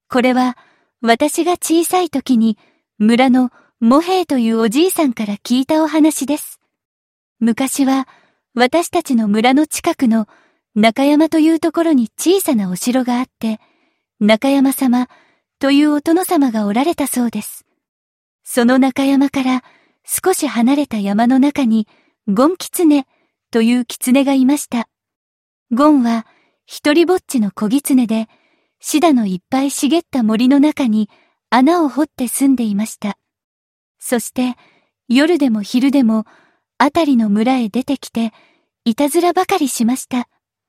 Voicepeakの桜乃そらにごんぎつねの冒頭読ませるとこんな感じ